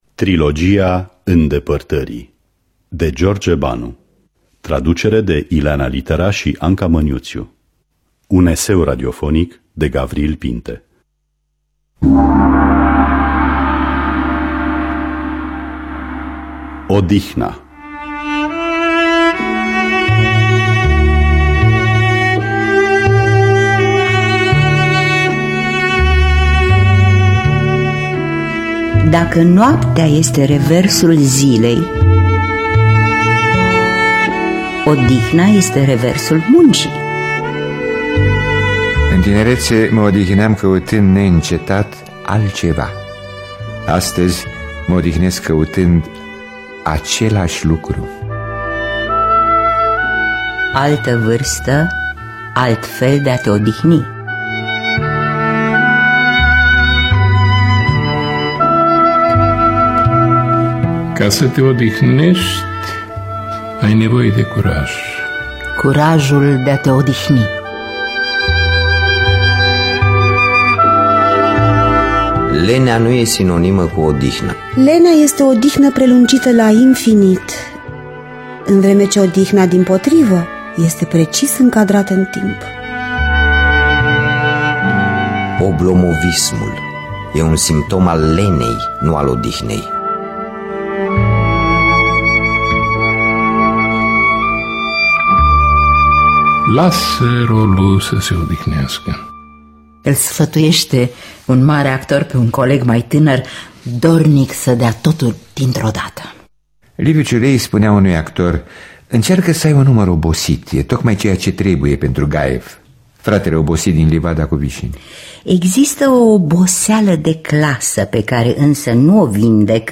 Un eseu radiofonic